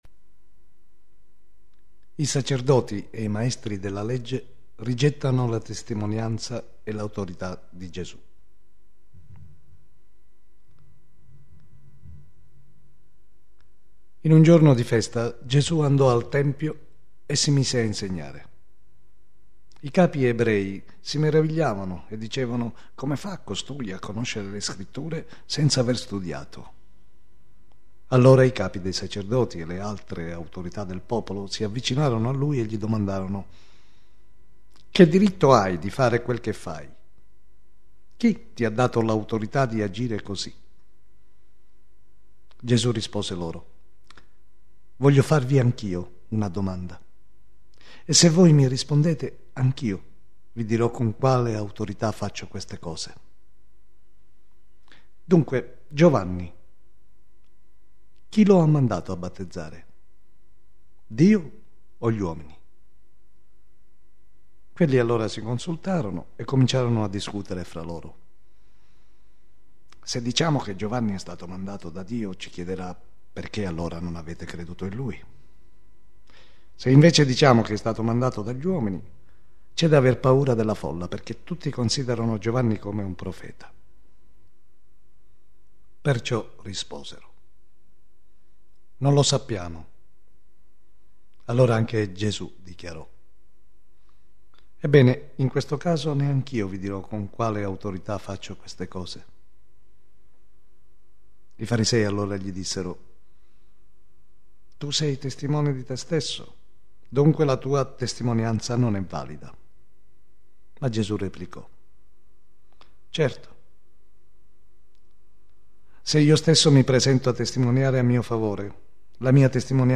Ascolta il brano letto